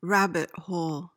PRONUNCIATION:
(RAB-it hohl)